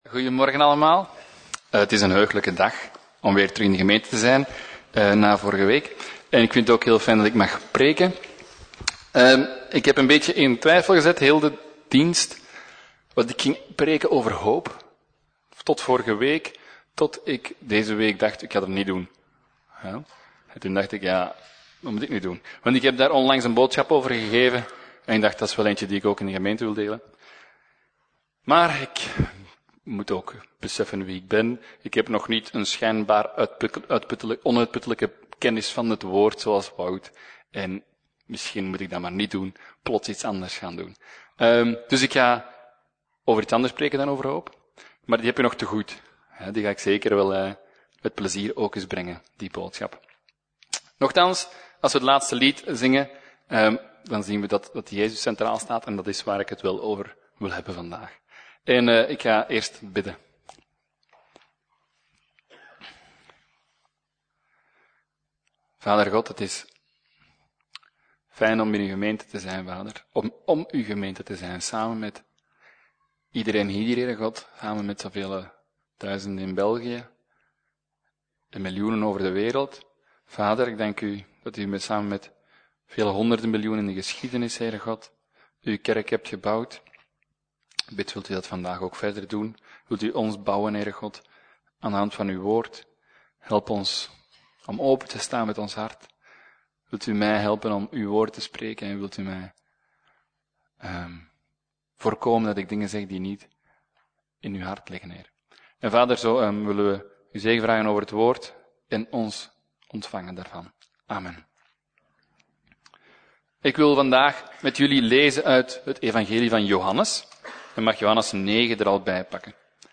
Preek: Blind - Levende Hoop